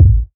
JJKicks (17).wav